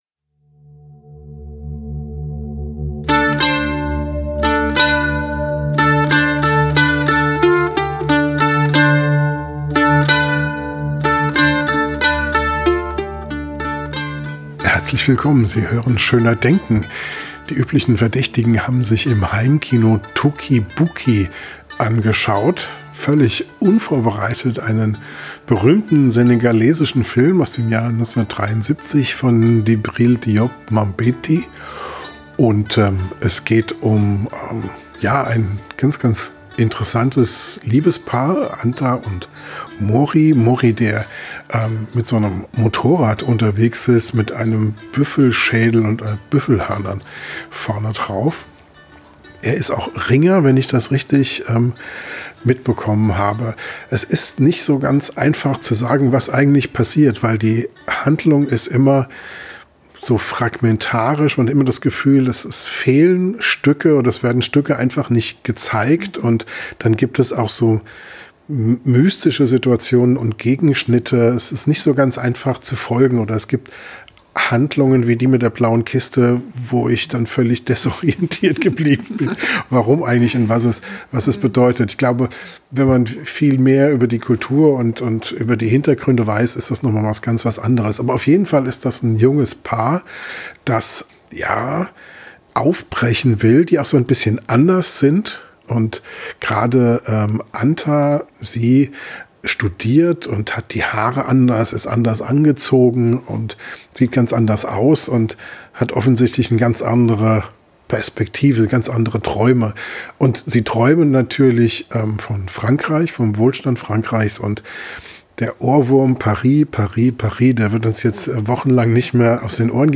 Am Mikrofon direkt nach dem Film